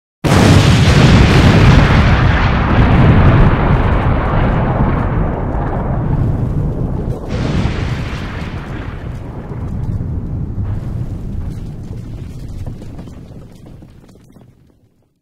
دانلود آهنگ انفجار 2 از افکت صوتی طبیعت و محیط
جلوه های صوتی
دانلود صدای انفجار 2 از ساعد نیوز با لینک مستقیم و کیفیت بالا